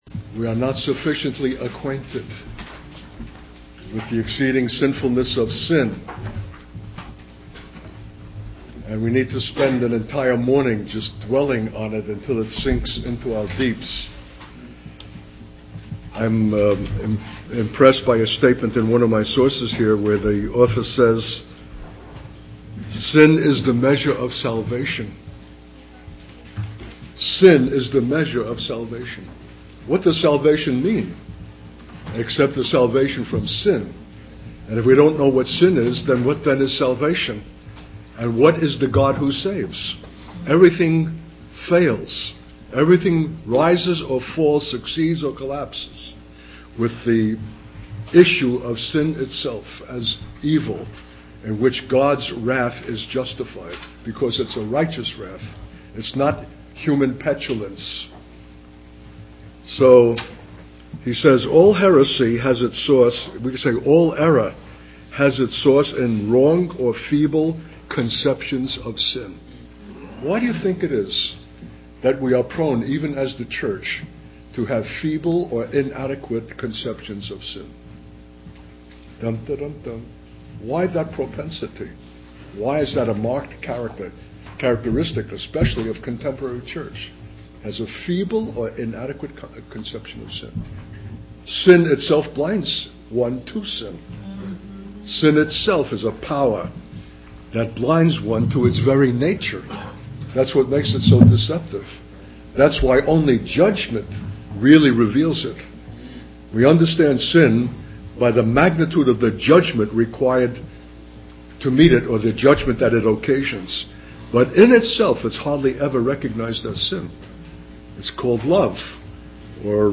In this sermon, the preacher emphasizes the importance of knowing and honoring God.